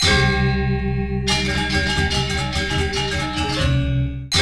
GAMELAN 8.wav